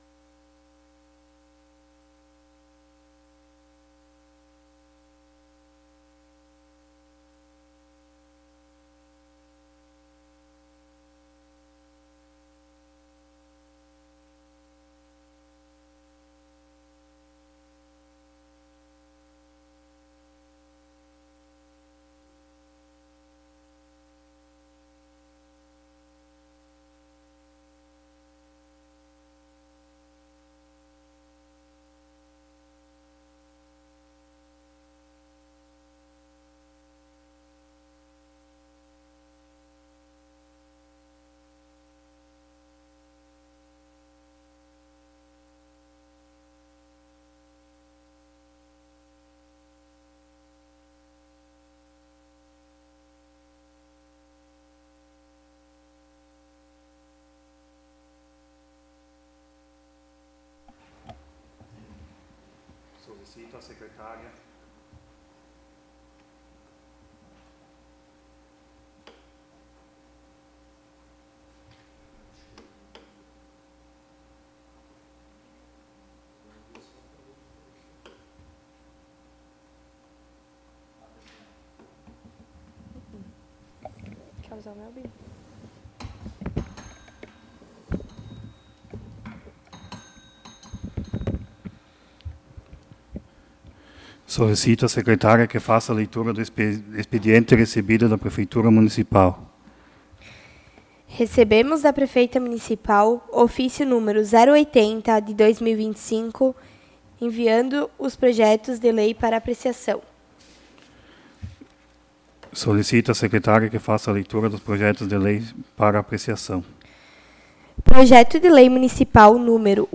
01 Sessão Extraordinária de 2025
Local: Câmara Municipal de Santa Tereza
Áudio da Sessão
Vereadores presentes